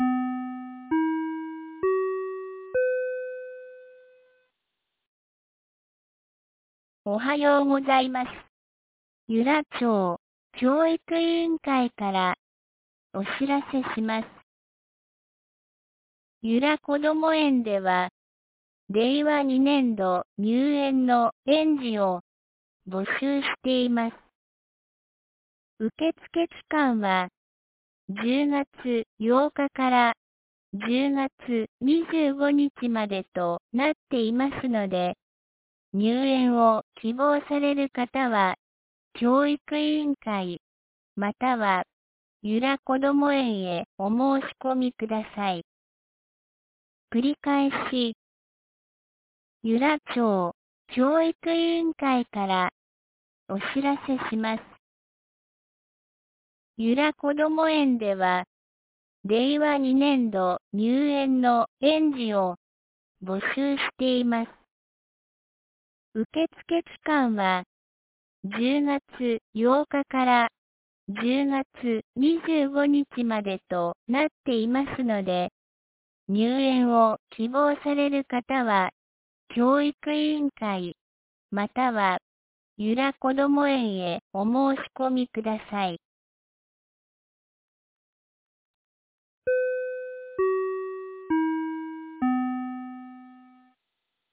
2019年10月09日 07時52分に、由良町から全地区へ放送がありました。